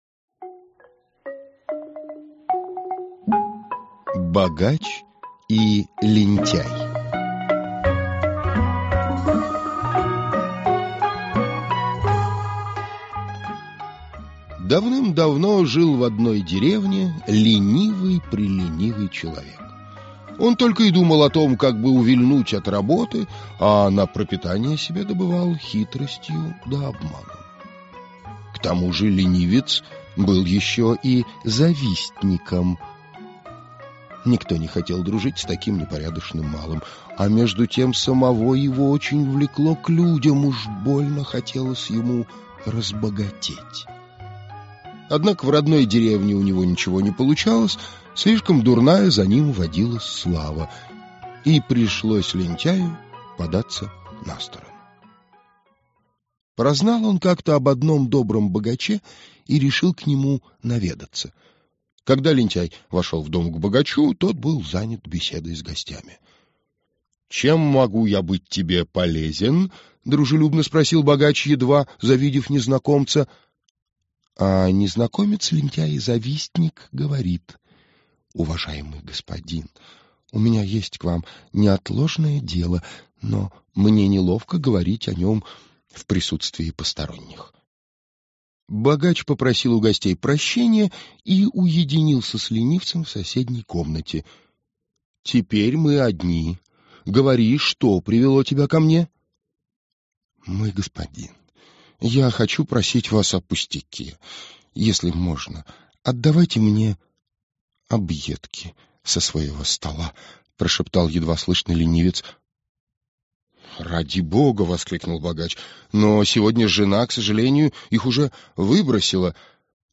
Аудиокнига Сказки народов Азии. Бирма | Библиотека аудиокниг